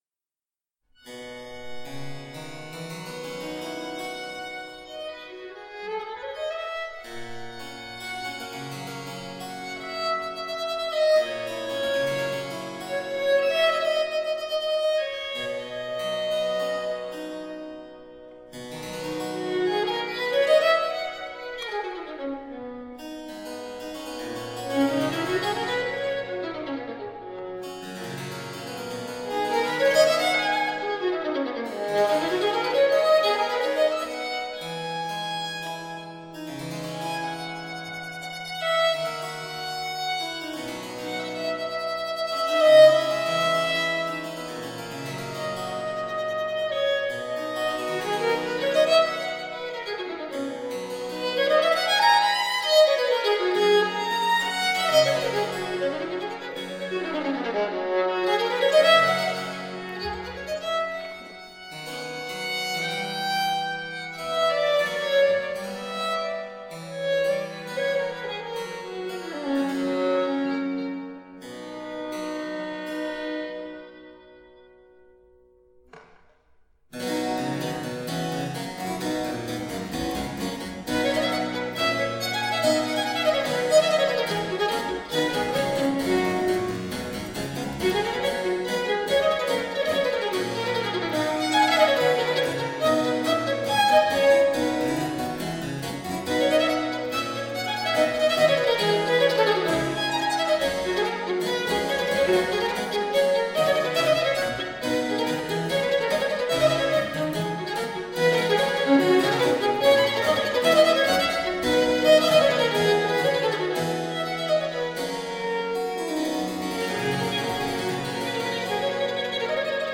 Small baroque chamber ensemble.
Tagged as: Classical, Chamber Music, Baroque, Instrumental